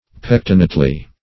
pectinately - definition of pectinately - synonyms, pronunciation, spelling from Free Dictionary Search Result for " pectinately" : The Collaborative International Dictionary of English v.0.48: Pectinately \Pec"ti*nate*ly\, adv. In a pectinate manner.
pectinately.mp3